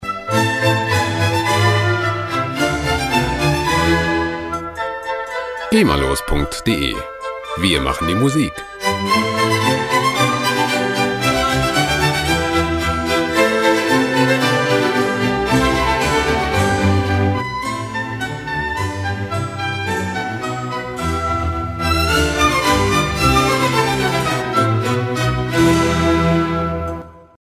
Gema-freie Klassik Loops
Musikstil: Barock Musik
Tempo: 110 bpm